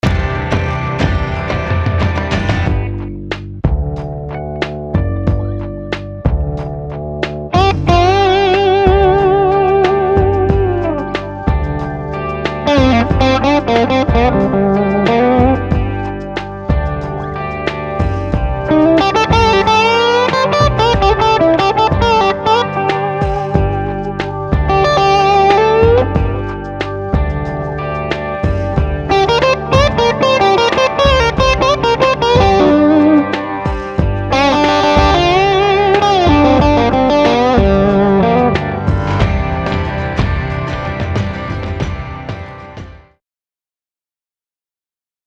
Und so klingen diese Pickups auch: Satt und blumig wie ein schwerer Bourdeaux.
Sie besitzen einen unglaublich lyrischen, Cello-artigen Charakter, der dunkel und geheimnisvoll klingt. Sie büßen etwas von jener Offenheit ein, die eigentlich typisch für den PAF ist. Dafür ist das Feuerwerk, das abgeht, wenn man härter anschlägt, von solch farbenfroher Natur, dass man sich schon nach ein paar Blues-Licks für einen verliebten Dichter halten könnte.